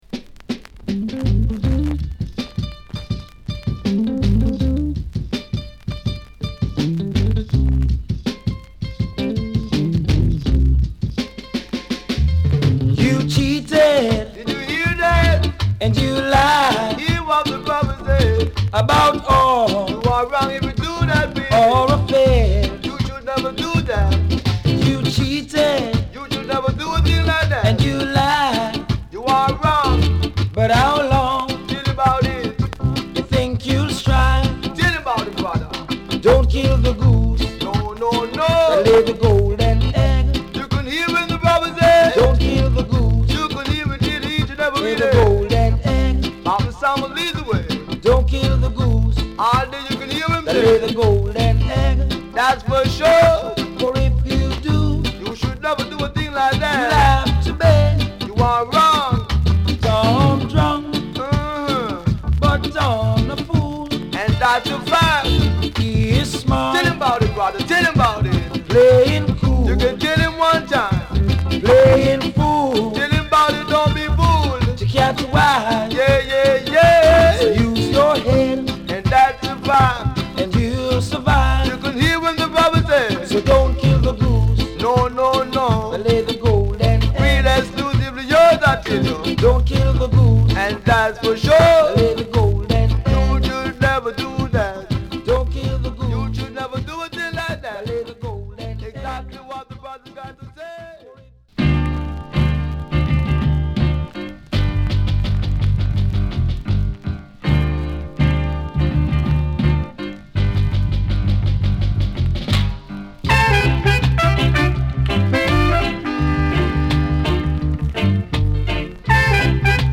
極上メロウインスト